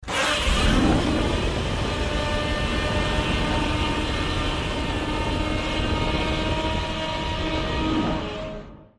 Звуки Годзиллы
Здесь вы найдете его мощный рев, грохот шагов, звуки разрушений и другие эффекты из фильмов и комиксов.
Грозный рык Годзиллы